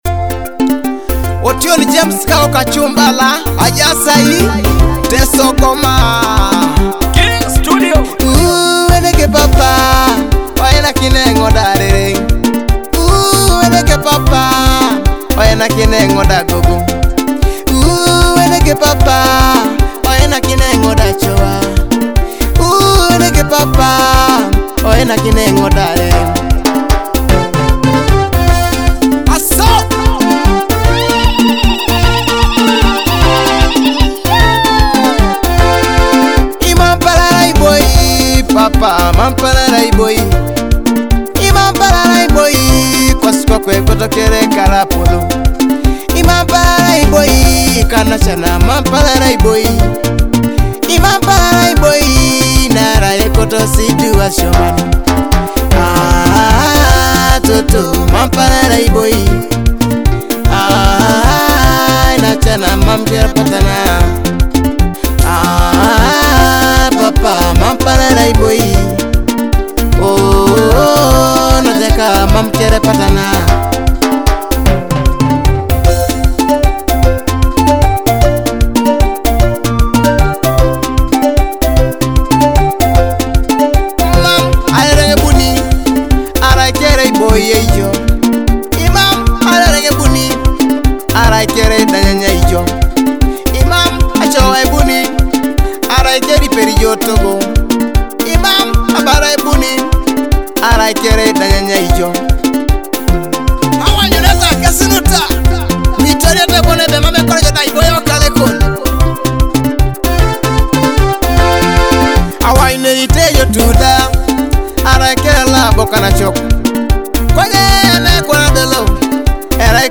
featuring joyful Ateso cultural and traditional rhythms